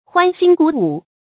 注音：ㄏㄨㄢ ㄒㄧㄣ ㄍㄨˇ ㄨˇ
歡欣鼓舞的讀法